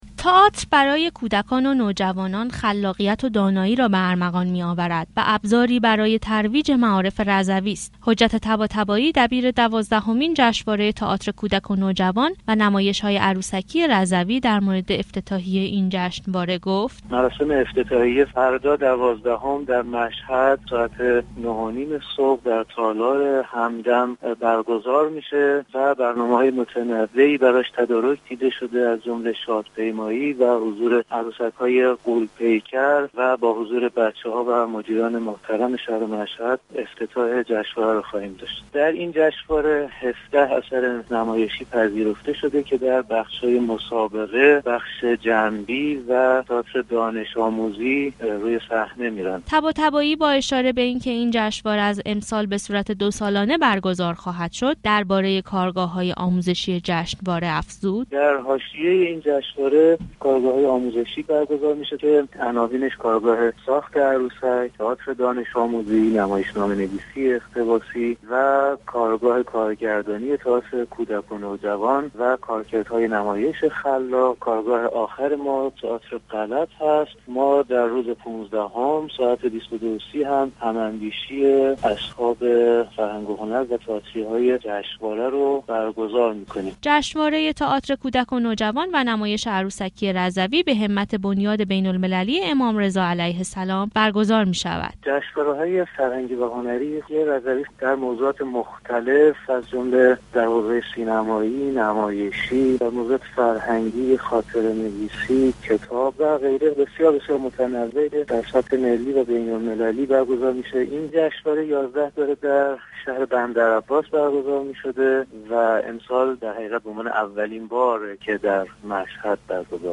نشست خبری دوازدهمین جشنواره تئاتر کودک و نوجوان و نمایش عروسکی رضوی در مجتمع فرهنگی امام رضا (ع) مشهد برگزار شد